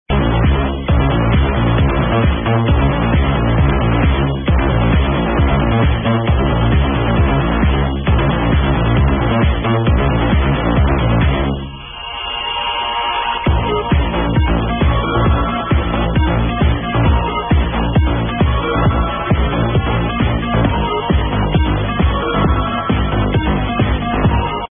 Recorded it off the radio.. any clues?